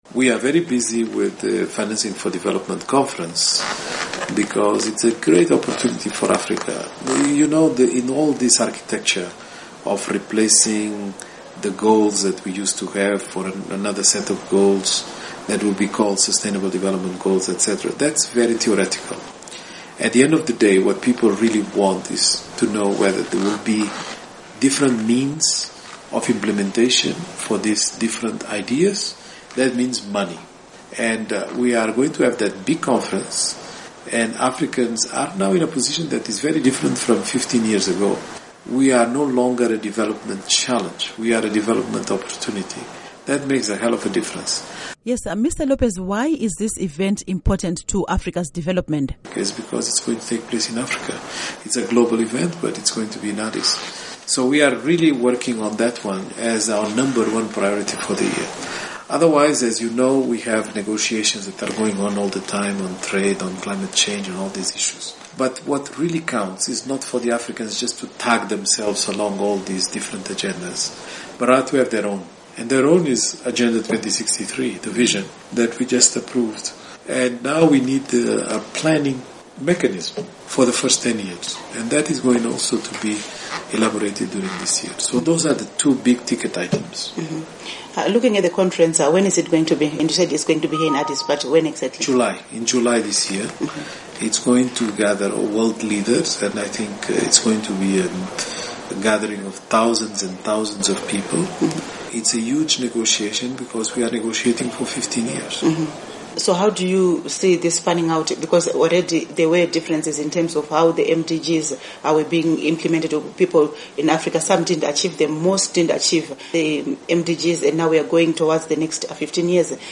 In an interview with the VOA, Lopes said the forthcoming Financing for Development Conference presents a great opportunity for the continent’s leaders to advance their agenda as the world discusses the post-2015 agenda.
Interview With Carlos Lopes